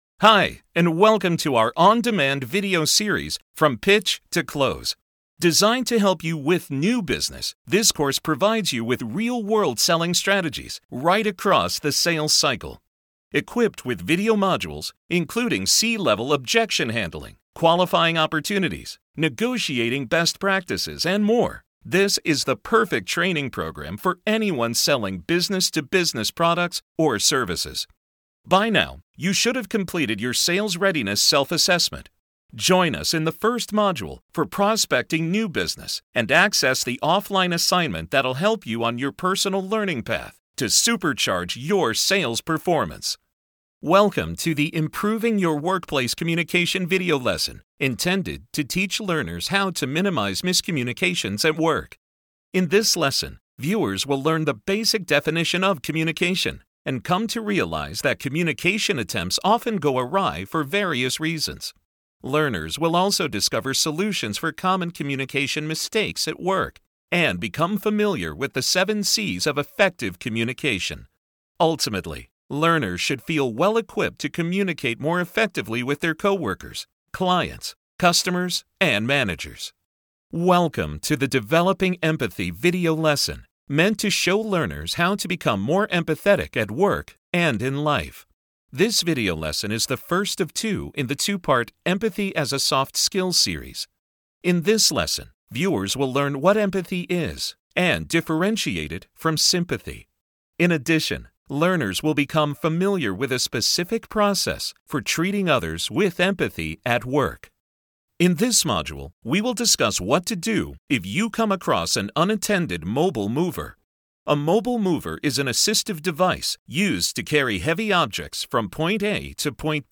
Academic Your favorite teacher in your favorite class.
Academic Demo